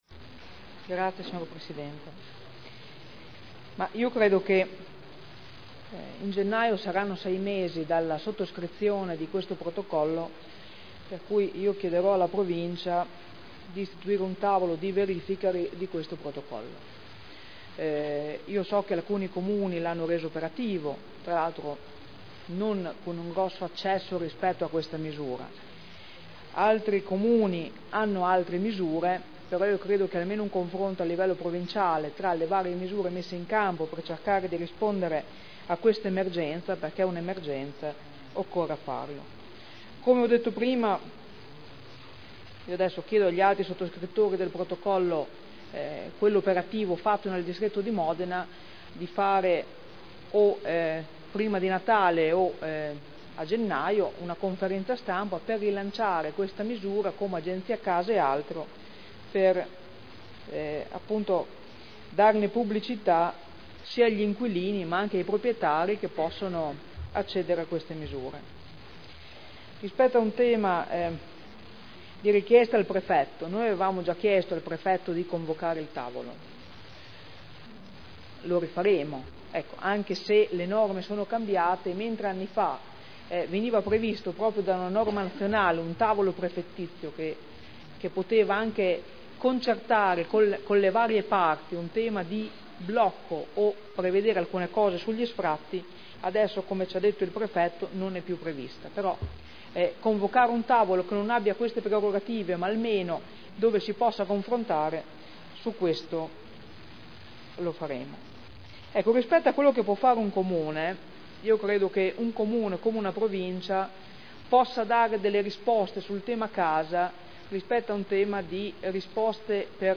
Francesca Maletti — Sito Audio Consiglio Comunale